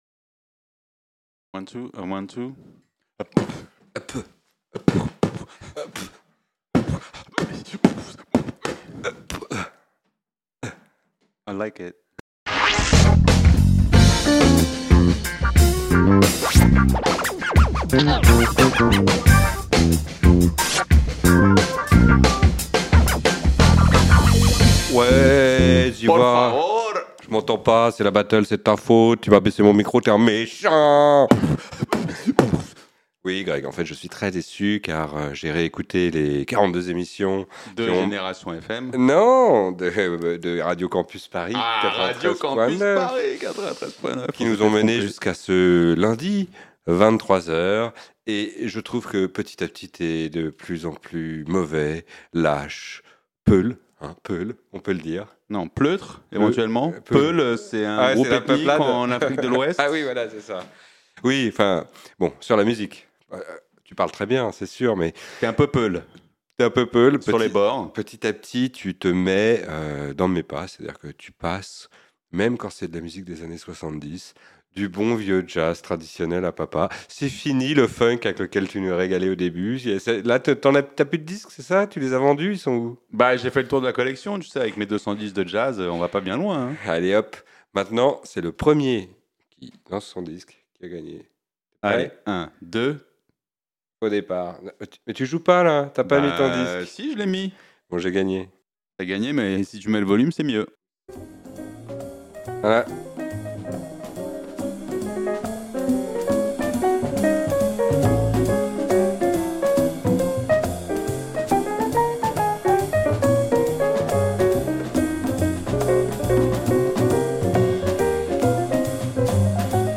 Oui, ça parle jazz ici.
Classique & jazz